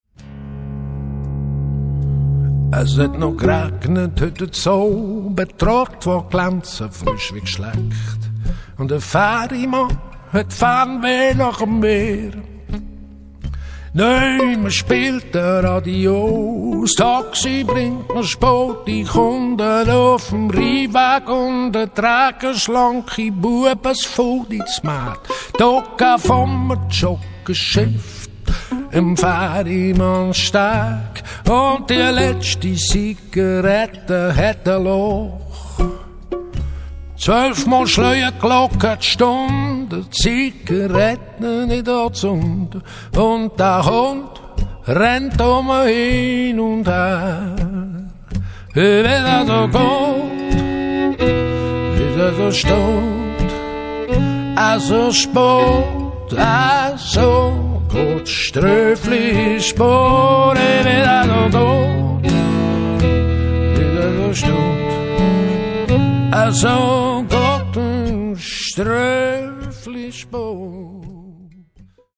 Eurostudio Wildenstein, Bubendorf / Schweiz.